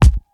• Smooth Steel Kick Drum G Key 209.wav
Royality free kick sound tuned to the G note. Loudest frequency: 342Hz
smooth-steel-kick-drum-g-key-209-ln2.wav